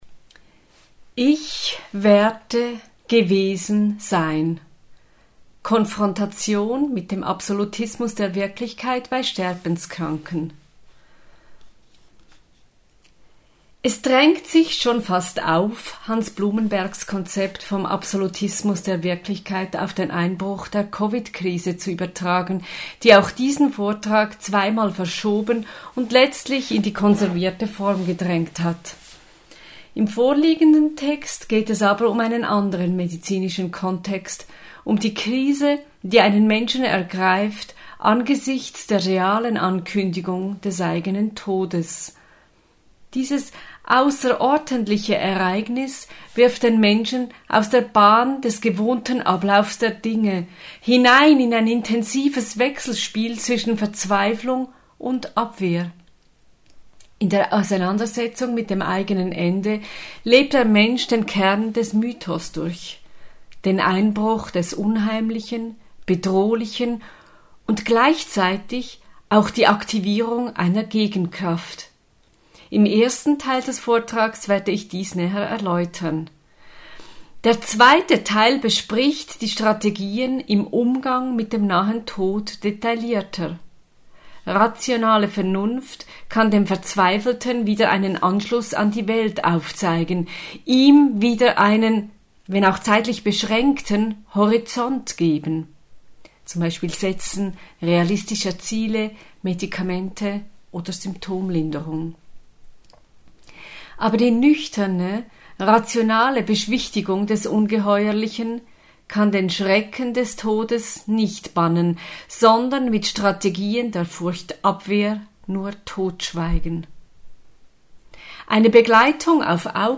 Vortrag wird als Podcast und Text hochgeladen!